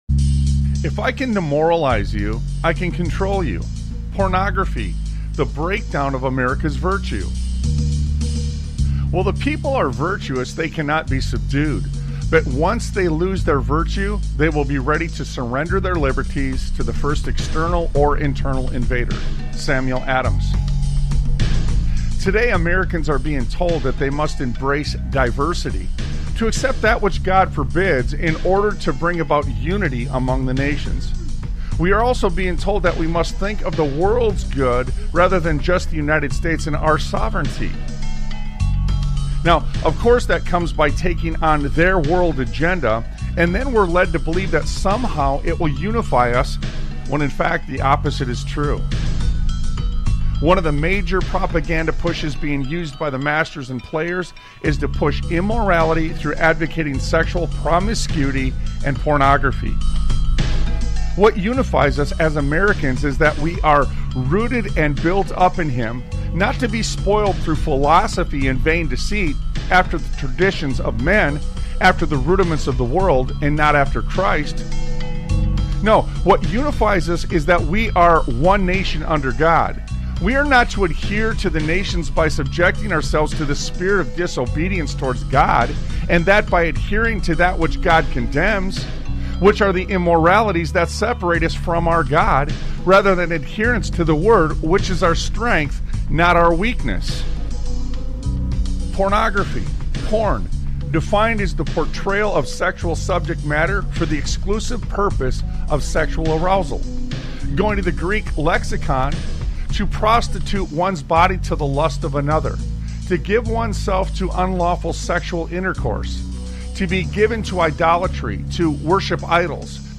Talk Show Episode, Audio Podcast, Sons of Liberty Radio and Clearing The Smoke on , show guests , about Clearing The Smoke, categorized as Education,History,Military,News,Politics & Government,Religion,Christianity,Society and Culture,Theory & Conspiracy